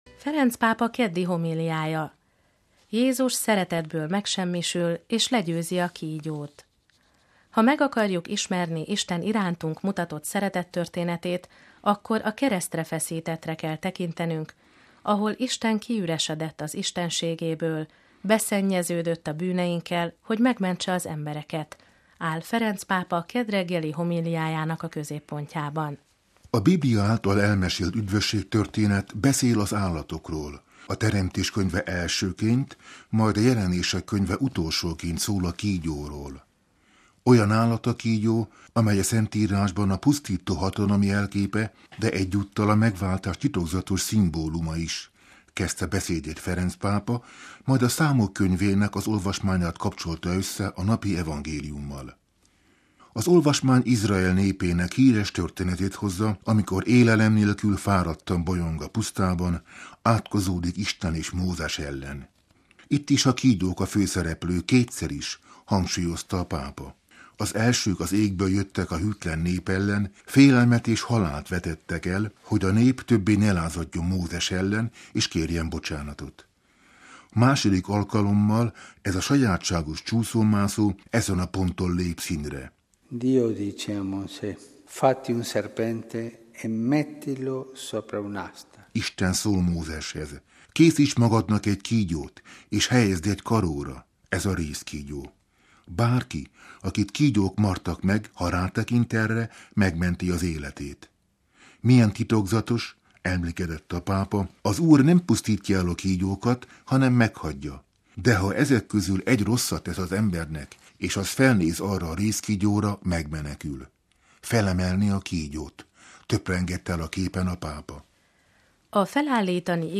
Ferenc pápa keddi homíliája: Jézus szeretetből megsemmisül és legyőzi a kígyót